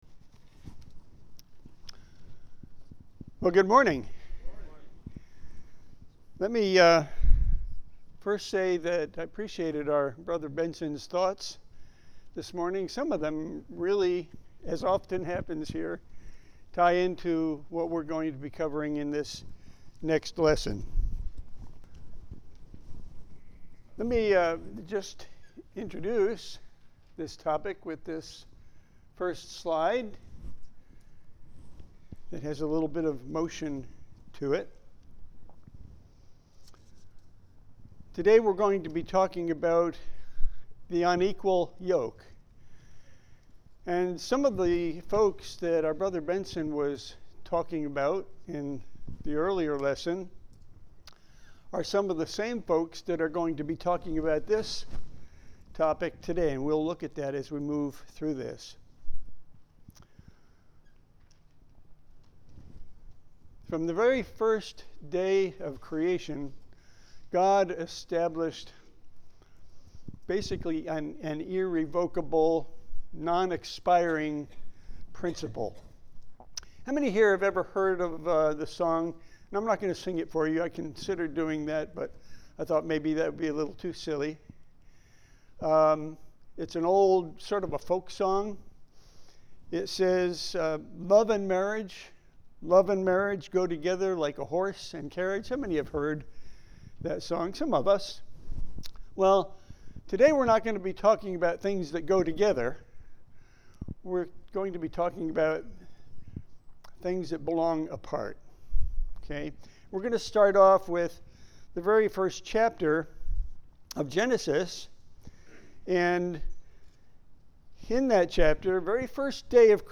Handouts showing Scriptures discussed in sermon audio